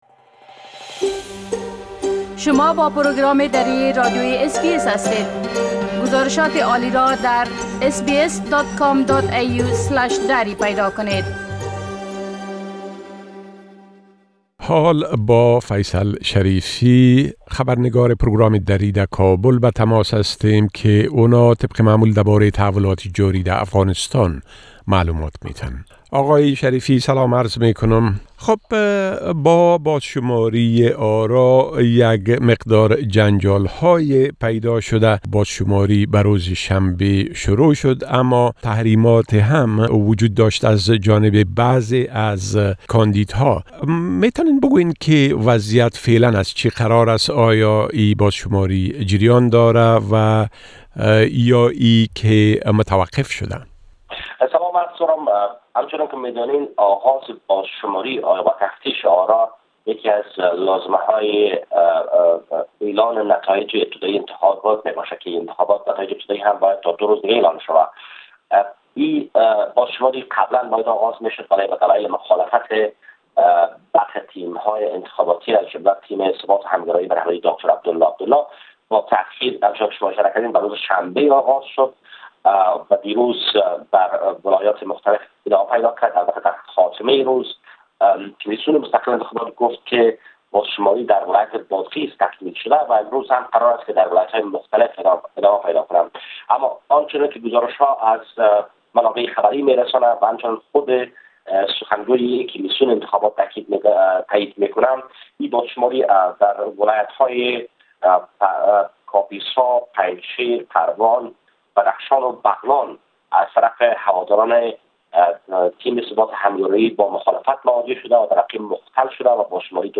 گرازش كامل خبرنگار ما در كابل به شمول مسايل مربوط به بازشمارى آرا و رويداد هاى مهم ديگر در افغانستان را در اينجا شنيده ميتوانيد.